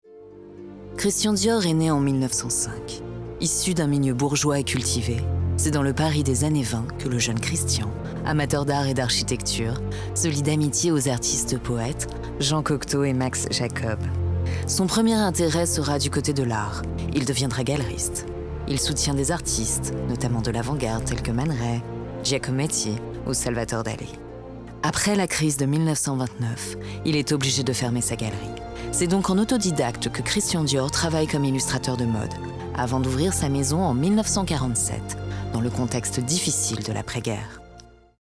Voix off
voix journaliste